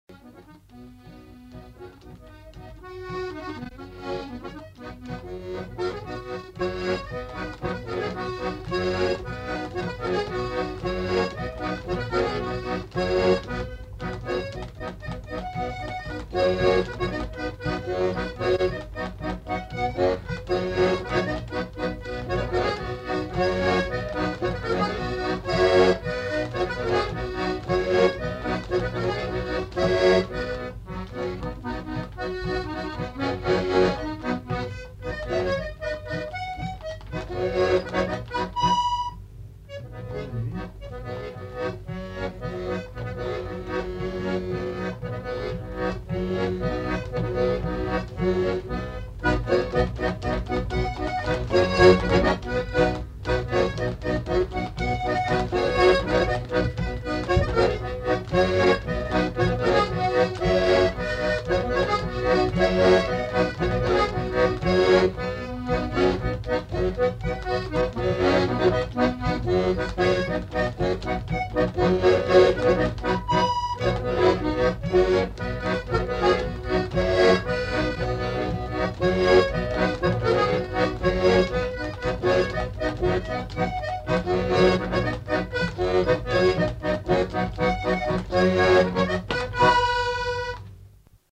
Lieu : Polastron
Genre : morceau instrumental
Instrument de musique : accordéon diatonique
Danse : polka des bébés
Ecouter-voir : archives sonores en ligne